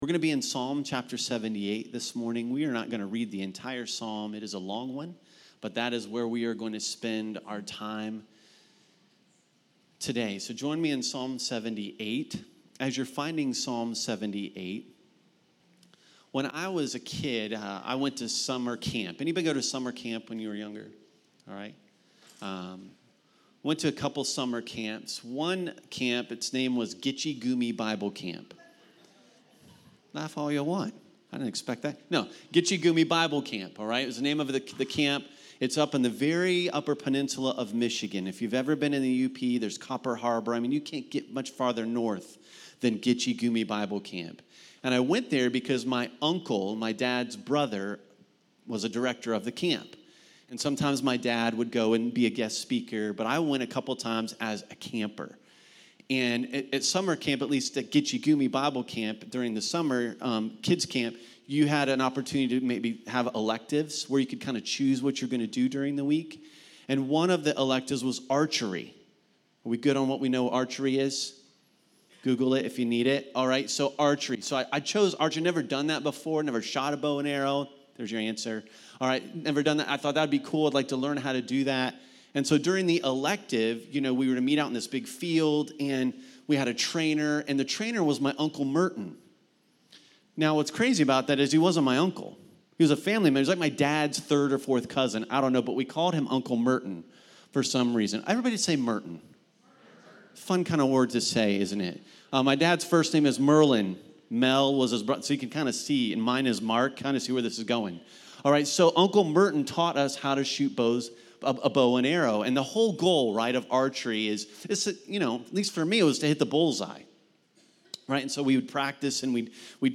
at Cincy Gathering